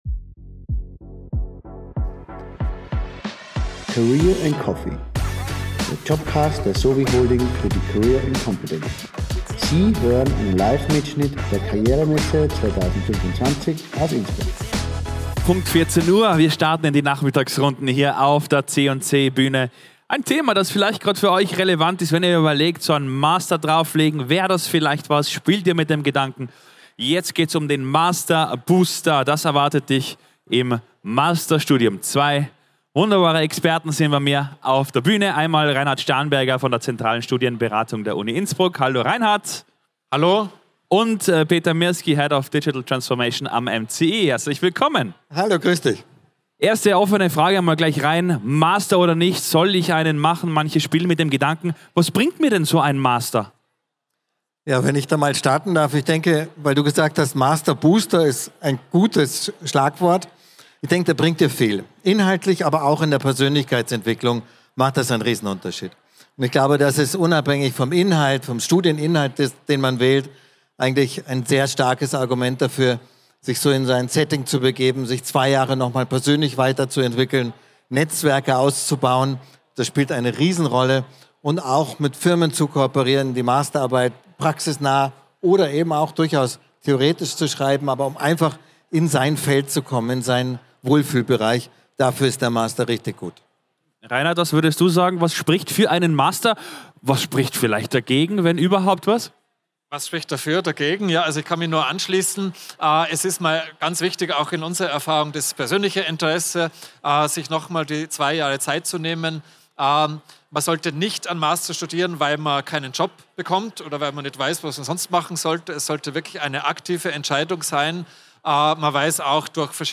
Livemitschnitt #8 von der career & competence am 14. Mai 2025 im Congress Innsbruck.